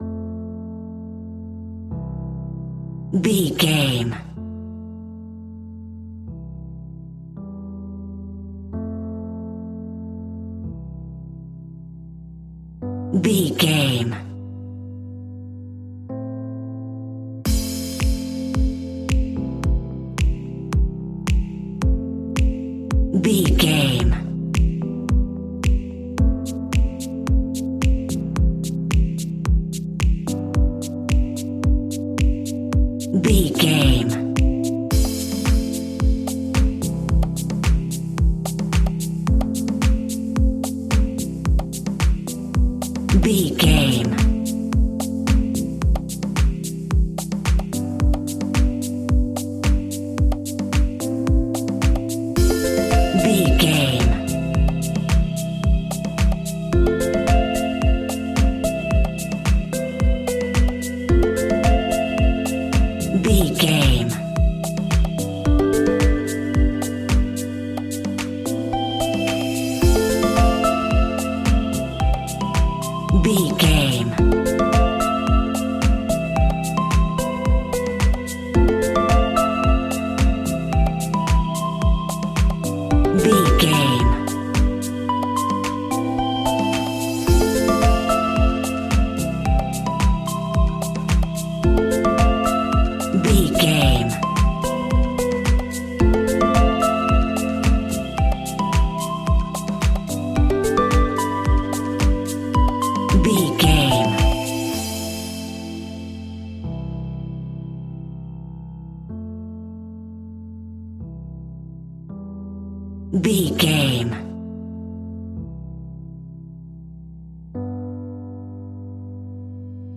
Aeolian/Minor
groovy
peaceful
meditative
smooth
drum machine
synthesiser
house
electro house
synth leads
synth bass